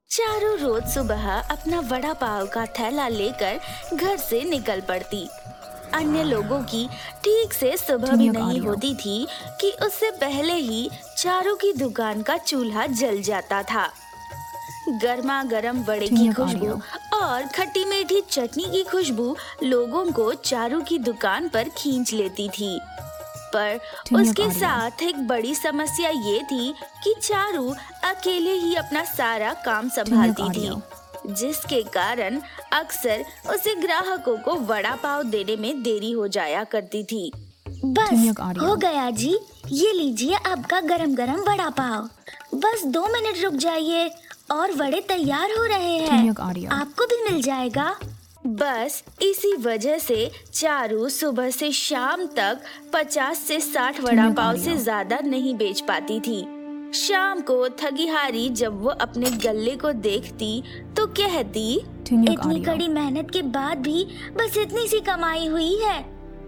Female Voice Artist